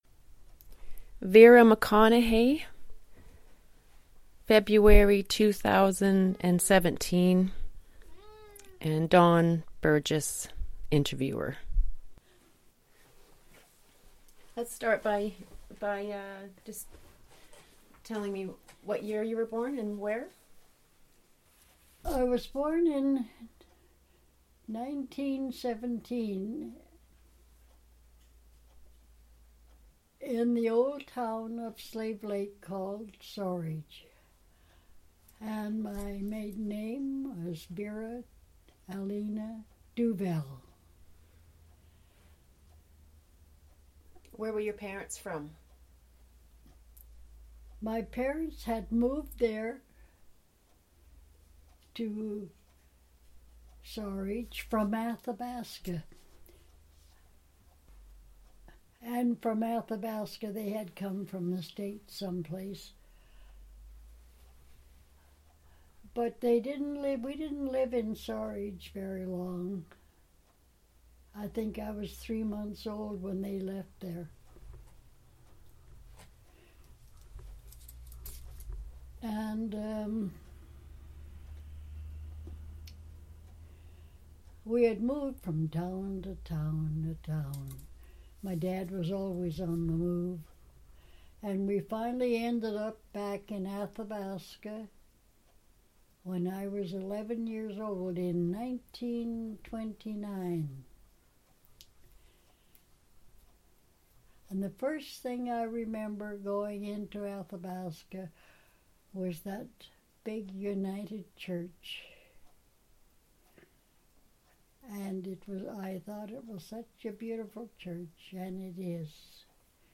Audio interview, transcript of audio interview and obituary,